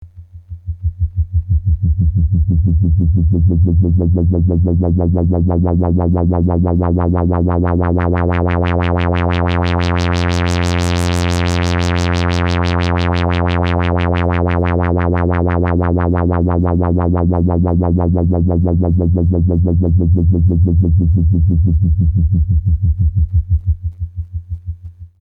Example sounds, no effects used, input signal is static saw wave from function generator:
filter sweep wobble, medium Q
ldr-filtersweep-wobble-saw88hz-medq.mp3